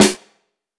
LT Snare (4).wav